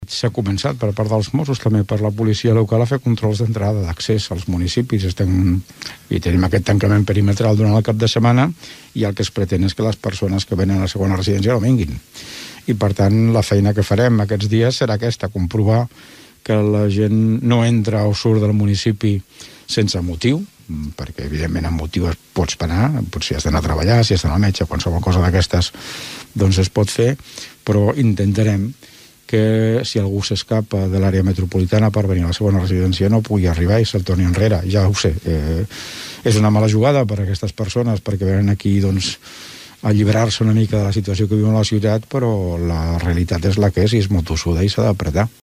L’Alcalde de Tordera, Joan Carles Garcia recorda que es vigilarà l’accés a segones residencies d’urbanitzacions i a qualsevol desplaçament no justificat.
alcalde-controls-policials.mp3